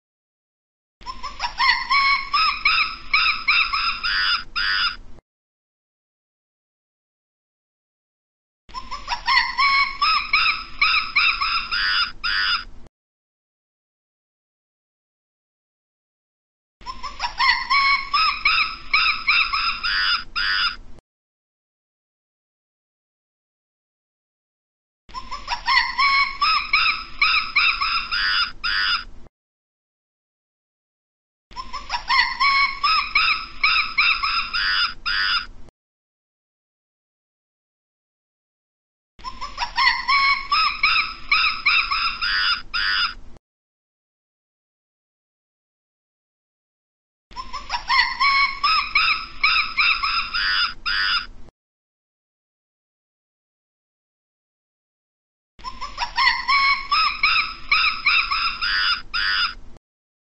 Tiếng Vượn Hú kêu mp3